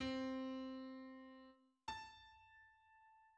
Tercdecyma wielka
melodycznie
harmonicznie   Tercdecyma o rozmiarze dwudziestu jeden półtonów.
Jest konsonansem niedoskonałym.